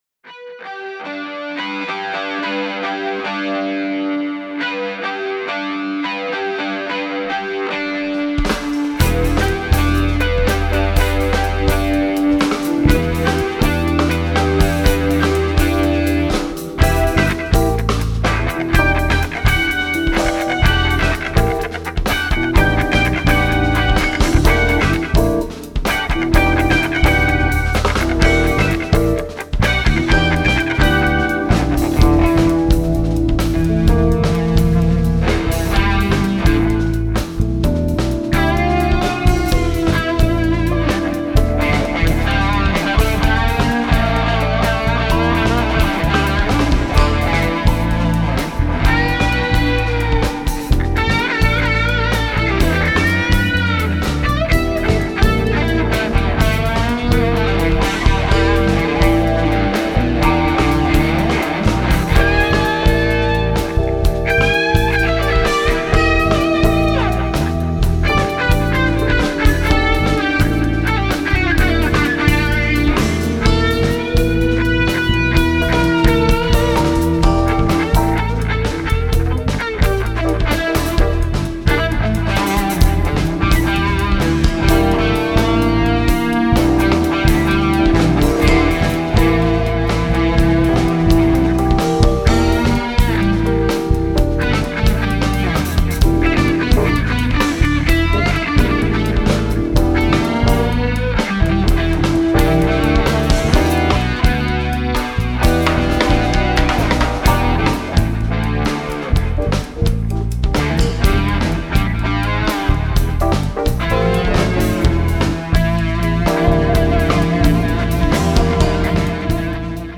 Experimental instrumental music
guitar